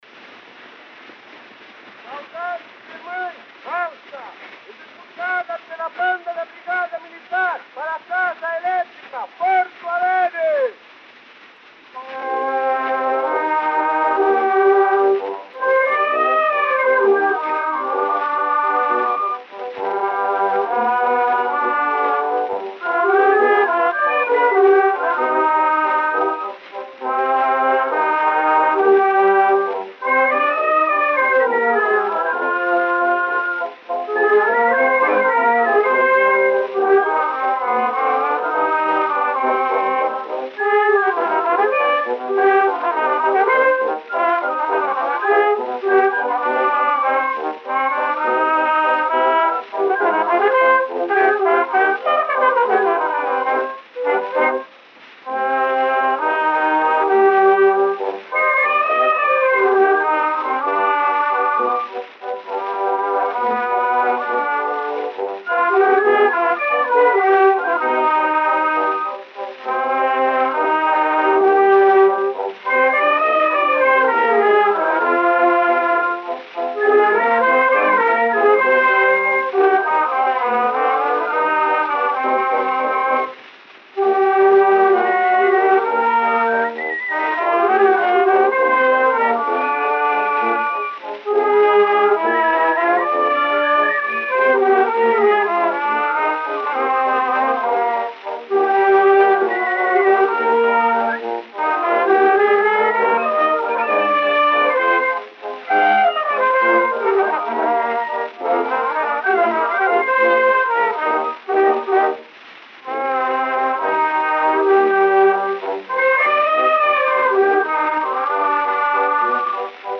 o gênero musical foi descrito como "Valsa" e